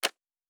pgs/Assets/Audio/Sci-Fi Sounds/Mechanical/Device Toggle 06.wav at master
Device Toggle 06.wav